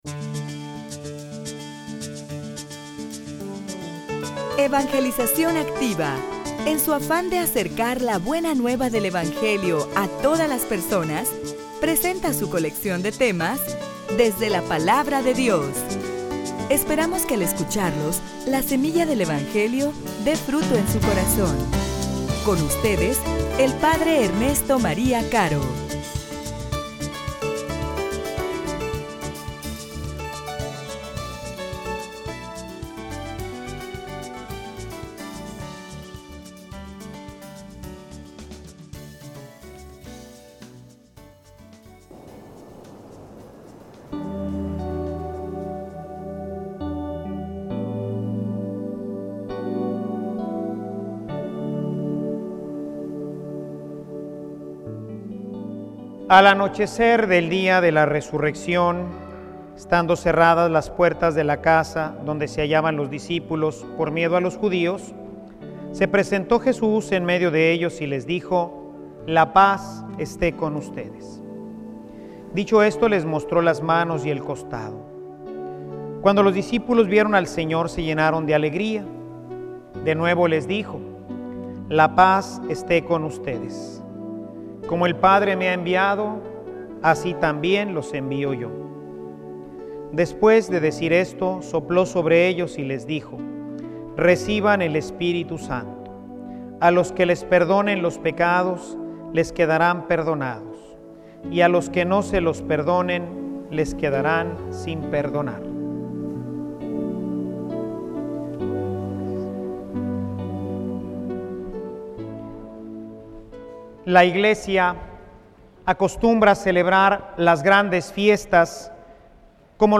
homilia_La_ley_se_convierte_en_Don.mp3